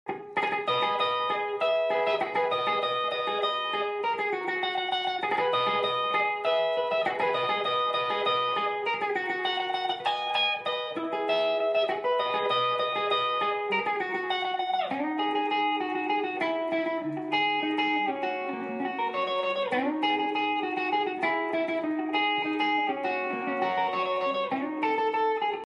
band solo